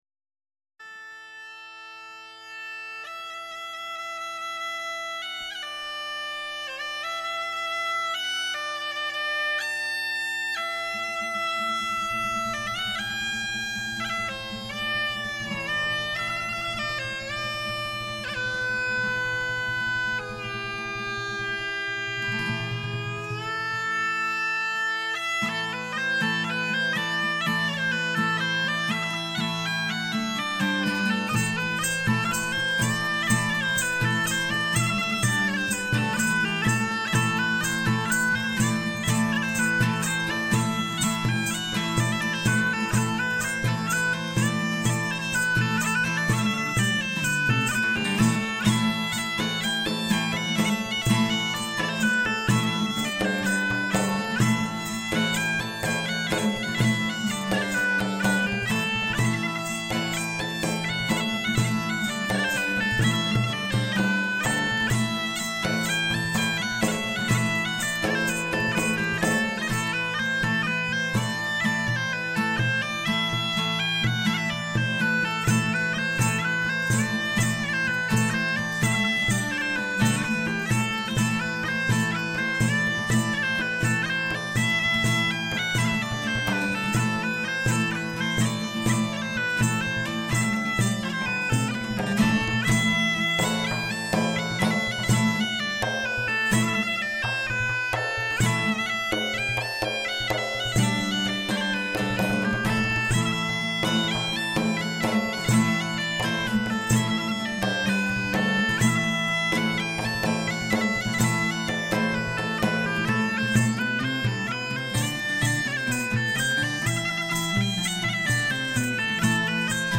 Για την ιστορία και την ηχητική βάση του εξαιρετικού μουσικού σχήματος με έδρα το Πήλιο που παίζει Μεσαιωνική, Κέλτικη, Σεφαρδίτικη μουσική κ.ά, το ενδιαφέρον τους για την κέλτικη και μεσαιωνική μουσική κουλτούρα και παράδοση, την απόδοση της ατμόσφαιρας της εποχής και τα Μεσαιωνικά Φεστιβάλ, τους συσχετισμούς της Κέλτικης μουσικής με την ελληνική παραδοσιακή, τα μουσικά όργανα, τη θεματολογία των τραγουδιών κ.ά. μιλούν στον 9,58 τα μέλη των TreesCelts Medieval